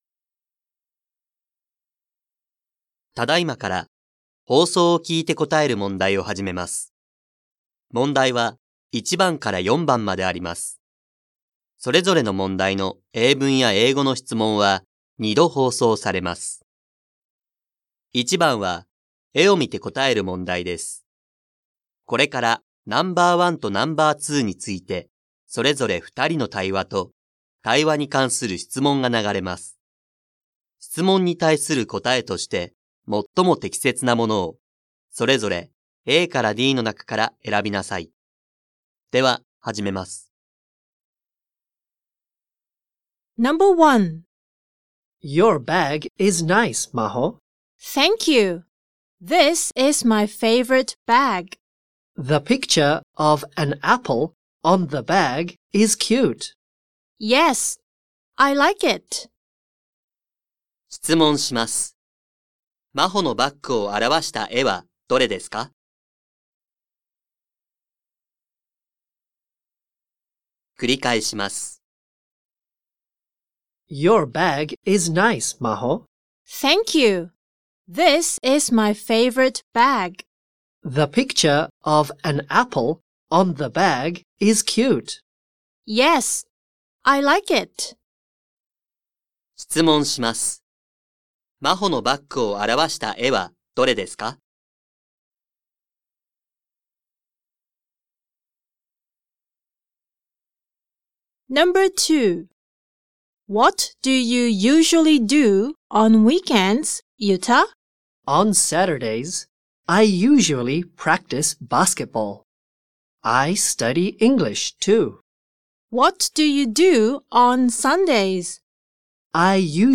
2025年度１年２号英語のリスニングテストの音声